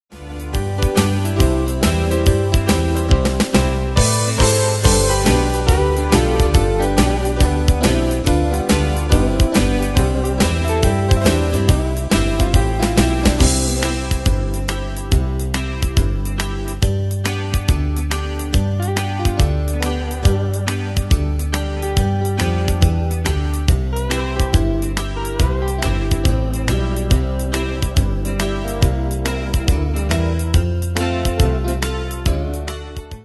Demos Midi Audio
Style: Country Année/Year: 1997 Tempo: 140 Durée/Time: 2.37
Danse/Dance: TwoSteps Cat Id.